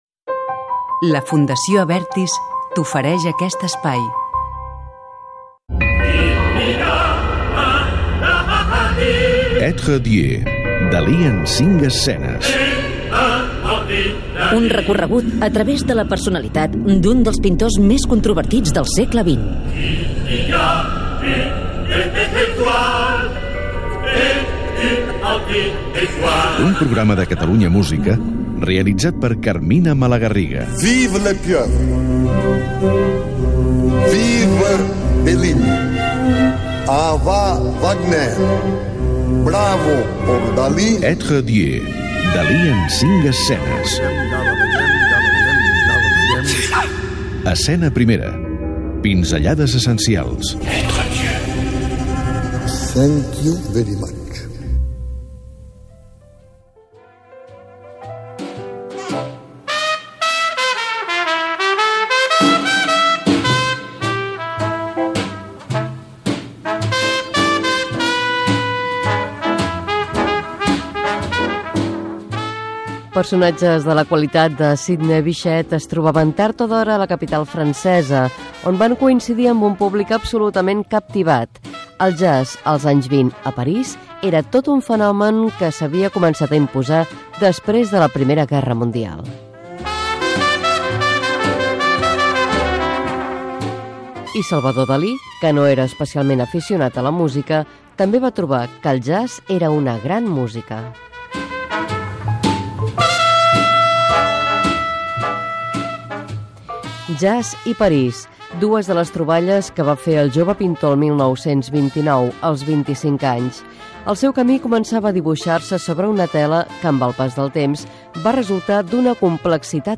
Publicitat, careta del programa.